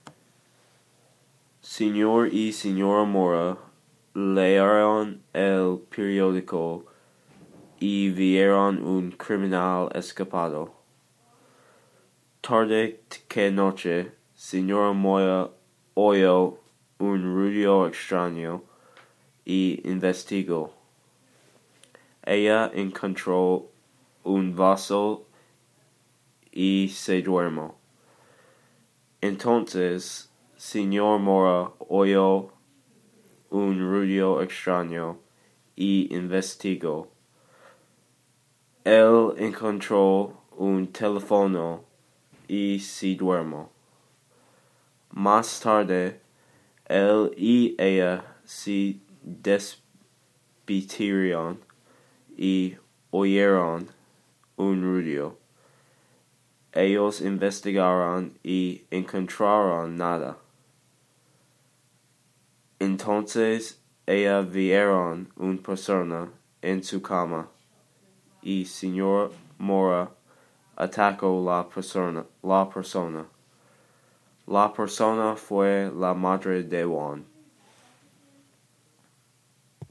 ruido extrano